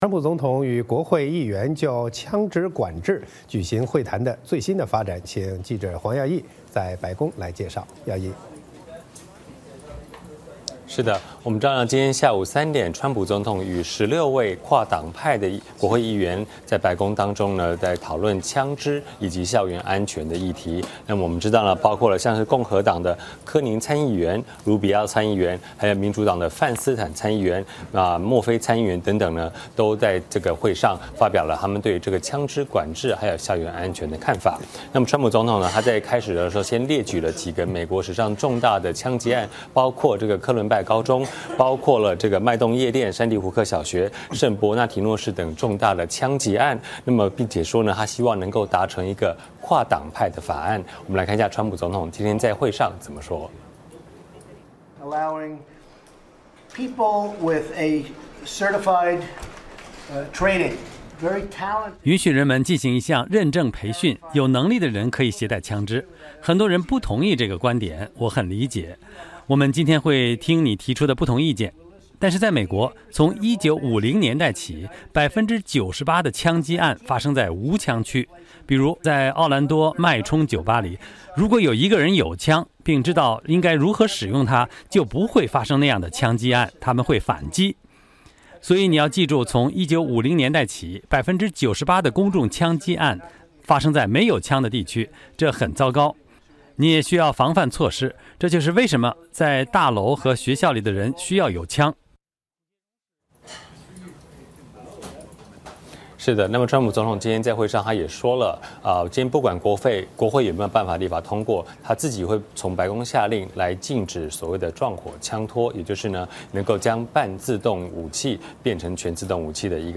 白宫 —